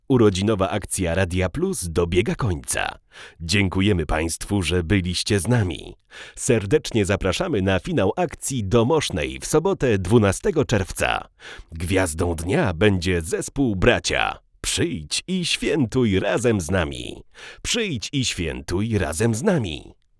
Nowa 67ka praktycznie nie szumi.